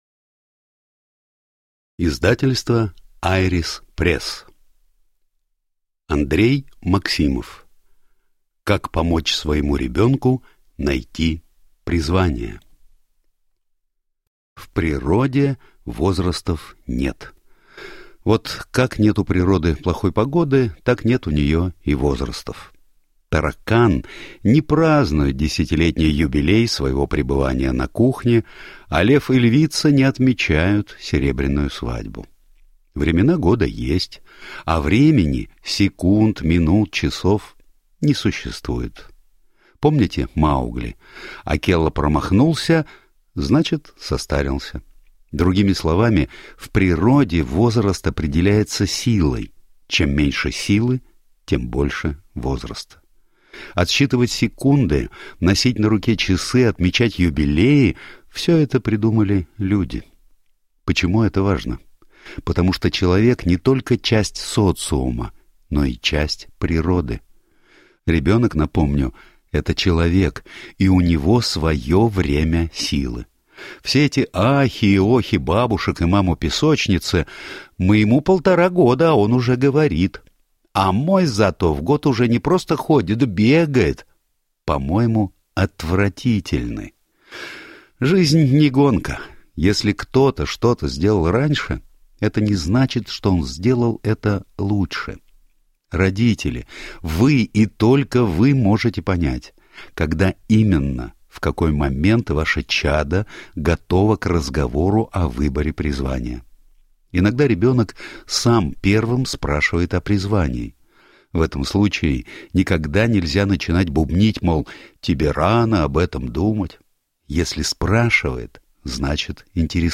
Аудиокнига Как помочь своему ребенку найти призвание | Библиотека аудиокниг